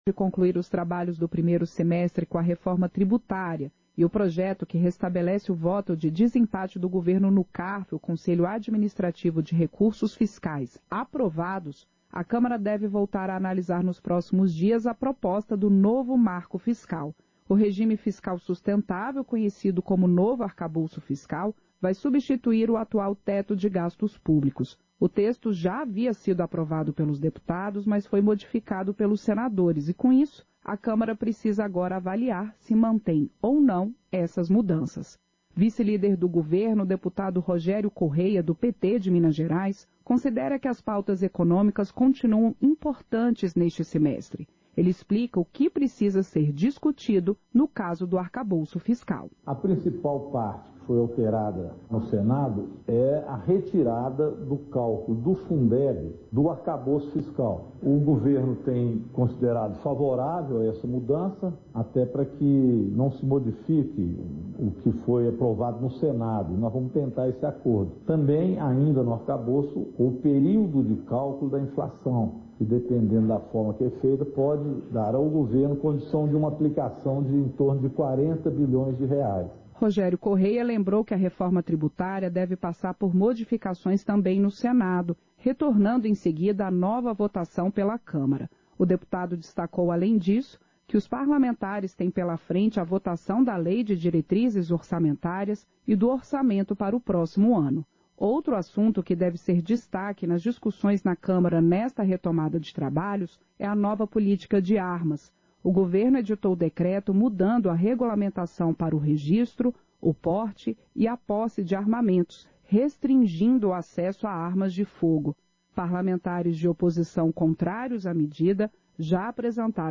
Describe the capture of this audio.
Sessão Ordinária 24/2023